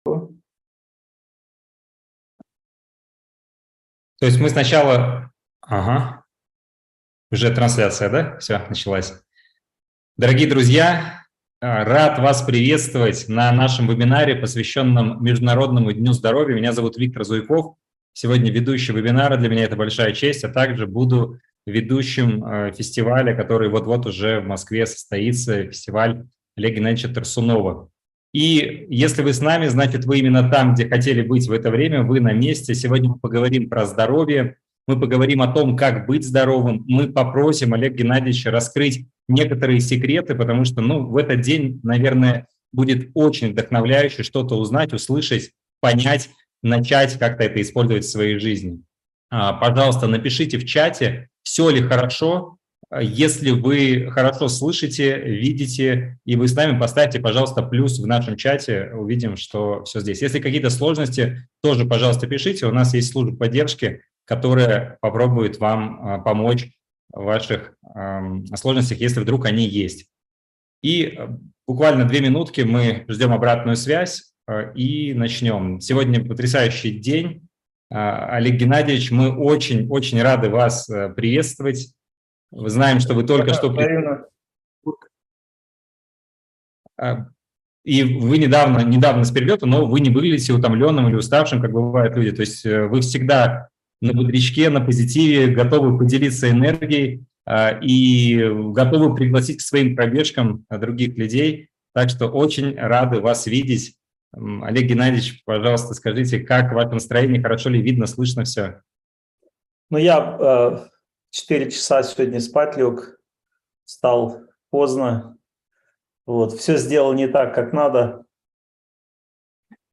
Зарядись здоровьем! (вебинар, 2023)